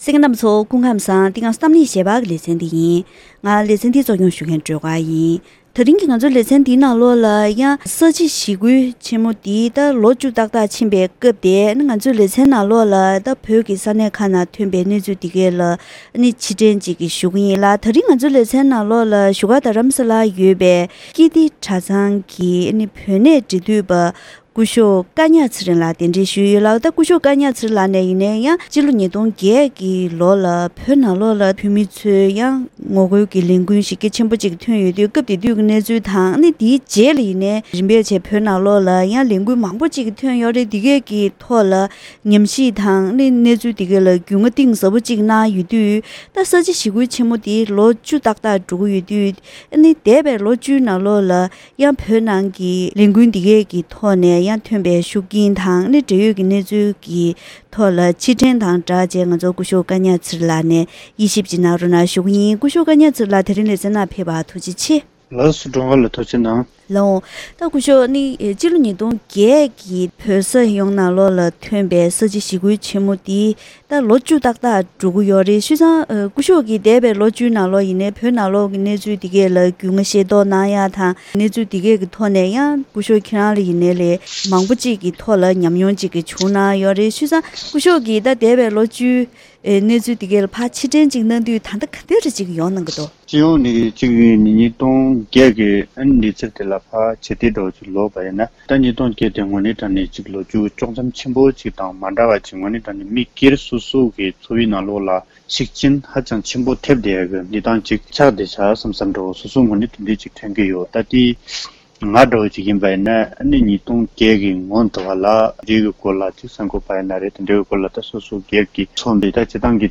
ཐེངས་འདིའི་གཏམ་གླེང་ཞལ་པར་ལེ་ཚན་ནང་སྤྱི་ལོ་༢༠༠༨ལོར་བོད་ས་ཡོངས་ནང་ས་བྱི་ཞི་རྒོལ་ཆེན་མོའི་ལས་འགུལ་འདི་ཐོན་ནས་ལོ་ངོ་བཅུ་ཏག་ཏག་འགྲོ་ཡི་ཡོད་པ་དང་། ཞི་རྒོལ་གྱི་ལས་འགུལ་འདིའི་ཁྲོད་ནས་བོད་ནང་གི་གཞོན་སྐྱེས་དང་གཞུང་གི་ལས་བྱེད་བོད་པ་ཚོར་ཤུགས་རྐྱེན་ཇི་བྱུང་དང་། ལས་འགུལ་ནང་དངོས་སུ་མཉམ་ཞུགས་གནང་མྱོང་ཡོད་མཁན་དང་བོད་ཀྱི་གནད་དོན་ལ་ཉམས་ཞིབ་གནང་མཁན་ནས་ལོ་ངོ་བཅུའི་རྗེས་གཟིགས་ཚུལ་གང་ཡོད་སོགས་ཀྱི་ཐད་བཀའ་མོལ་ཞུས་པ་ཞིག་གསན་རོགས་གནང་།